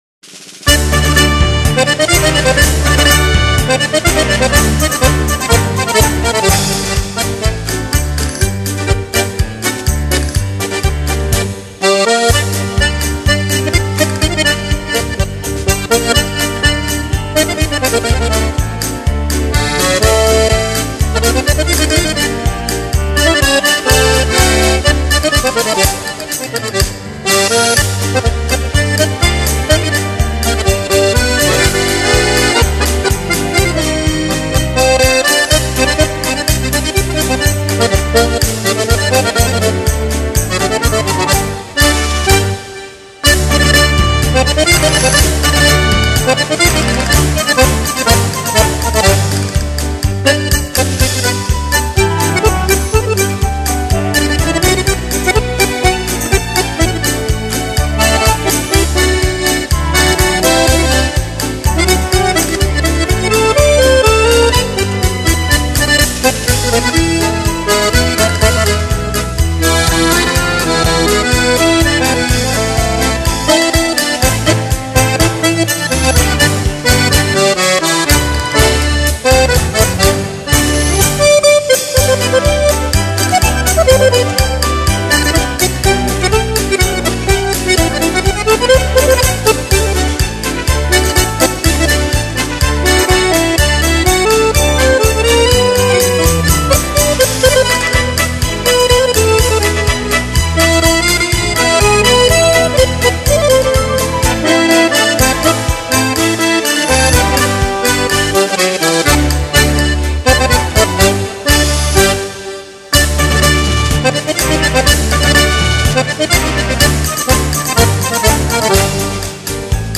浪漫手风琴